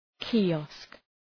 {ki:’ɒsk}